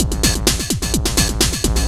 DS 128-BPM B3.wav